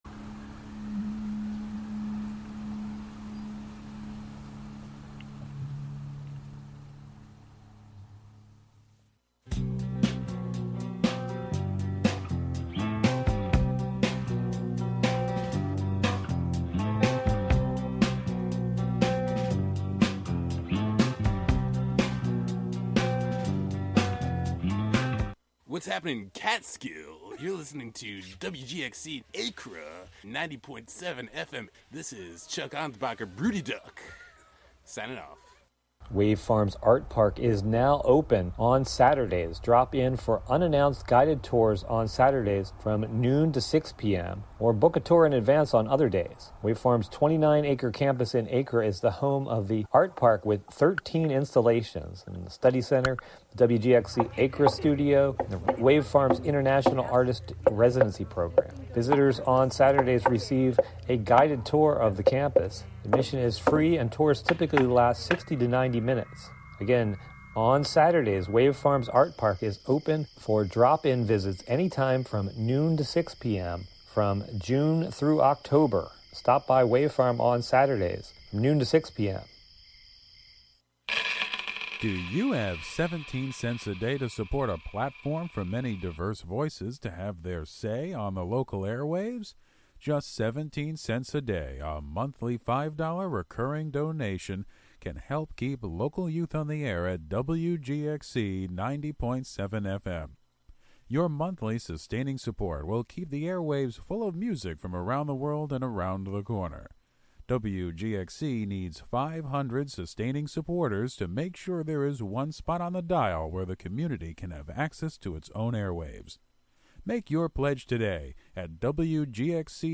entertainment news and reviews
and the "Forgotten Decades" music mix of lesser-played and/or misremembered songs from the '50s through the '90s.